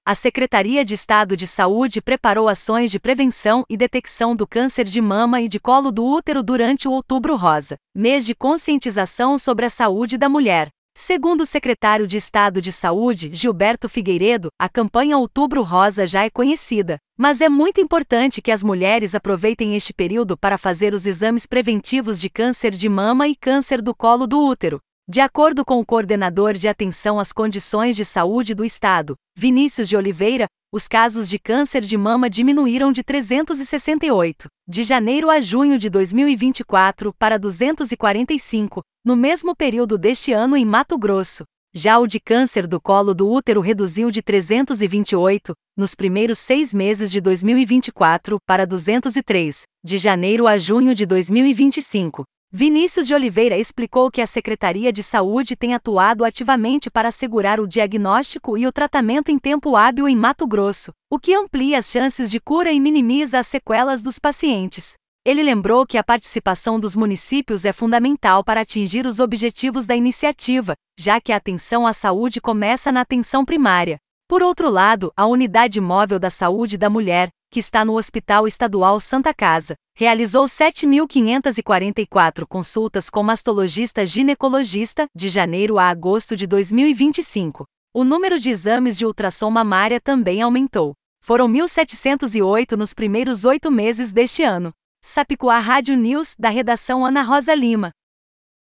Boletins de MT 02 out, 2025